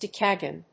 音節dec・a・gon 発音記号・読み方
/dékəgὰn(米国英語), dég(ə)n(英国英語)/